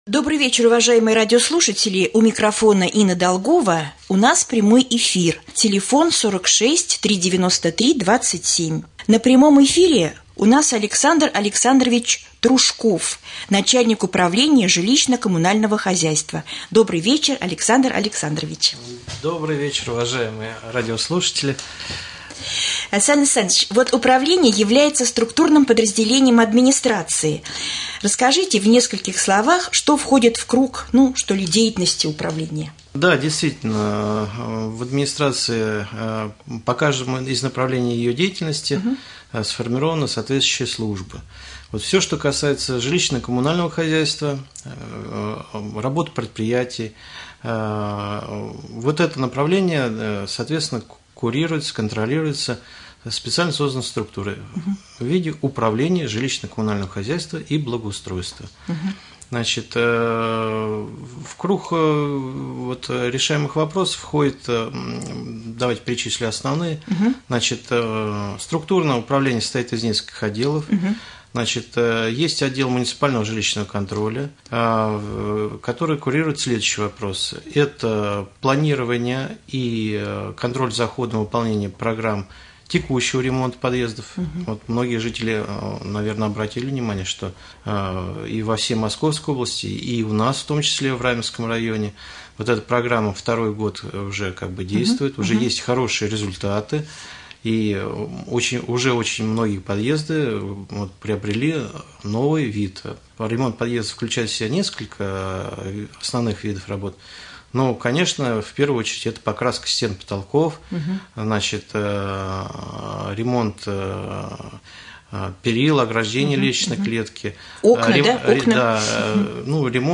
Гость студии — начальник Управления ЖКХ Александр Трушков
Прямой эфир.